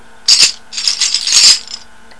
植物繊維の篭に、ボトルの王冠が入ってます。よく通る、かなり（大きい物ほど）にぎやかな音です。
バスケットラットル音